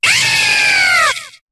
Cri de Zéblitz dans Pokémon HOME.